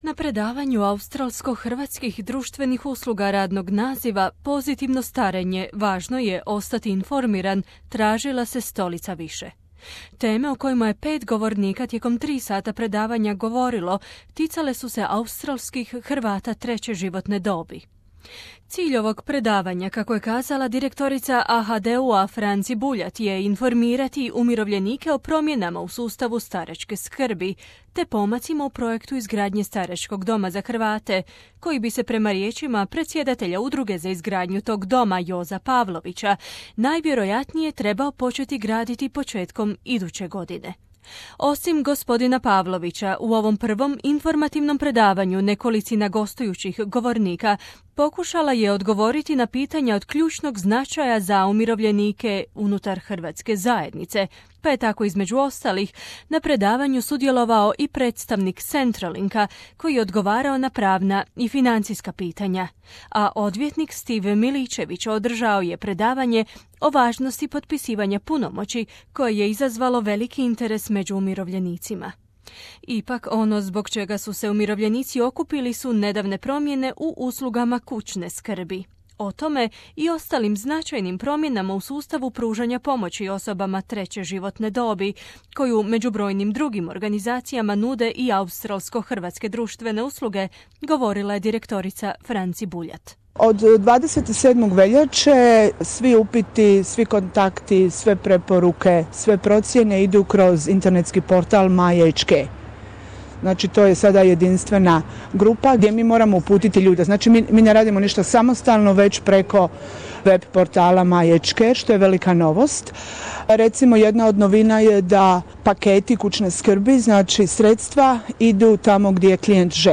Predavanje, na kojemu su prisustvovali i i gostujući govornici, je pred više od stotinu zainteresiranih umirovljenika održano u nedjelju, 21. svibnja u Hrvatskom domu u Footscrayu. Između ostalog, govorilo se o pravnom i financijskom planiranju, hrvatskom umirovljeničkom domu u Geelongu te kulturološki specifičnim uslugama dostupnim putem AHDU-a.